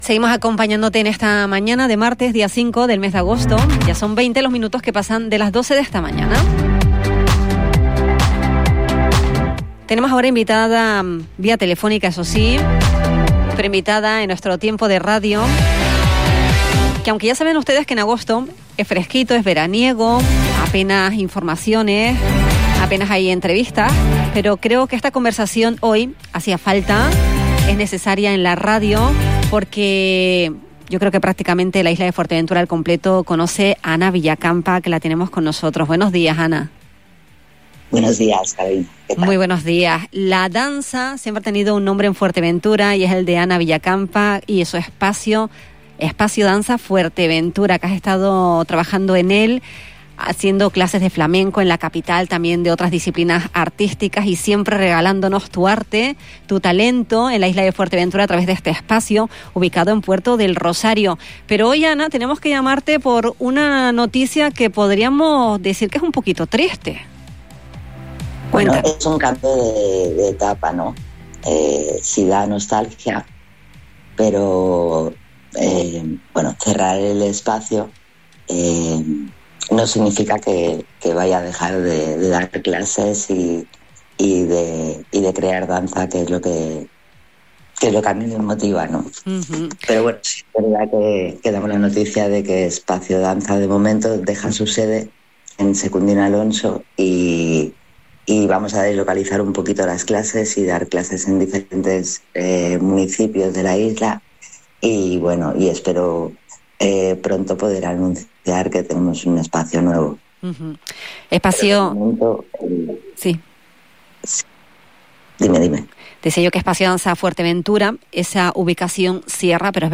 en una entrevista en La Mañana Xtra de Radio Insular.